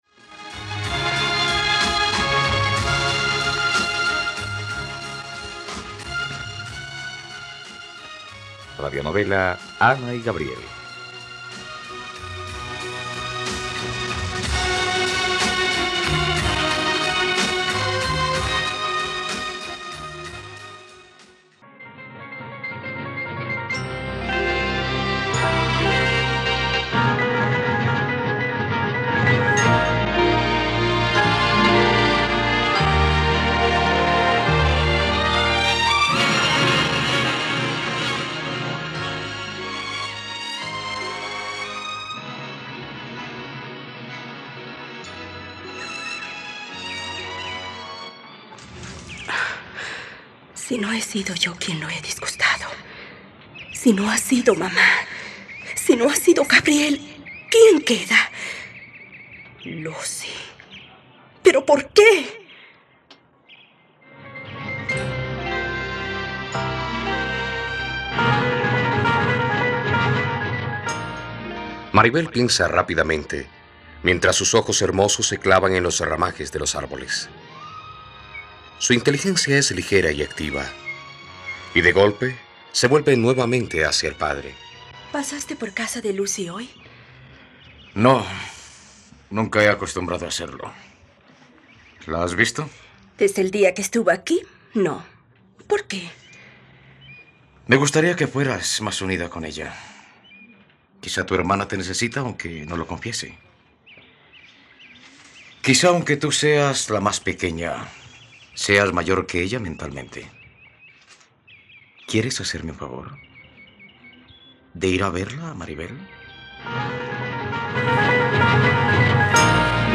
..Radionovela. Escucha ahora el capítulo 27 de la historia de amor de Ana y Gabriel en la plataforma de streaming de los colombianos: RTVCPlay.